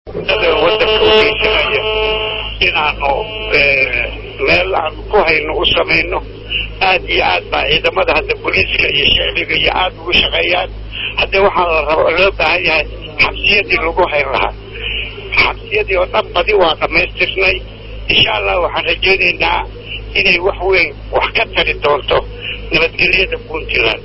Madaxweynaha Maamul Goboledka Puntland, Gen. Cadde Muuse, ayaa Isniin Maarso 24, 2008 waxa uu dhagax dhigay xabsi weyn oo laga dhisayo magaalada Garoowe.
Gen. Cadde Muuse oo meeshii dhagax dhigga ka hadlay waxa uu sheegay in ay dhamaystireen xabsiyo isagoo sheegay in uu rajayanayo xabsiyada la dhisayo in ay wax ka tari doonaan nabad gelyada Puntland, isagoo arrintaas ka hadlaayeyna waxa uu yiri: "Dadka dalka dhibka u geysanaya in aan meel aan ku hayno u samayno, aad iyo aad ayaa ciidamada boolisku u shaqeeyaan, waxaana loo baahan yahay xabsiyadii lagu hayn lahaa, xabsiyadii oo dhan badi waa dhamaystiray, insha Allah waxaan rajayneynaa in ay wax ka tari doonto nabad gelyada Puntland," ayuu yiri [